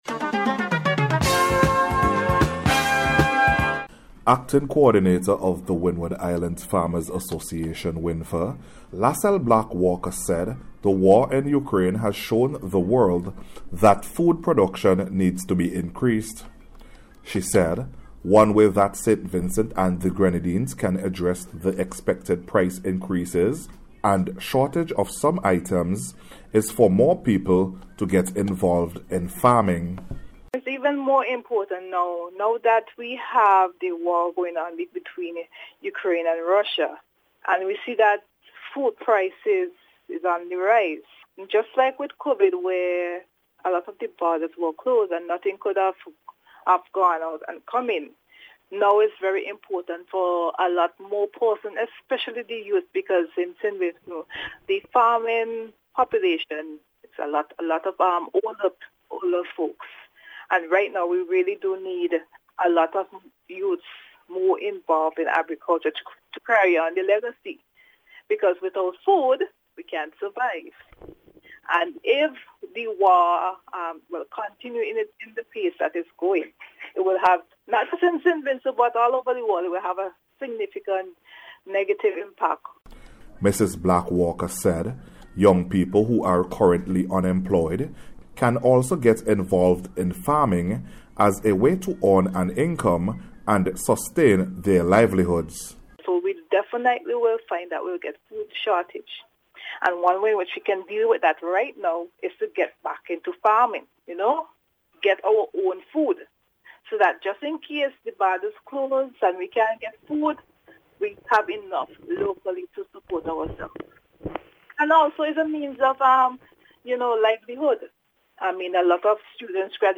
NBC’s Special Report for March 23rd 2022
WINFA-AND-INCREASED-PRODUCTION-REPORT.mp3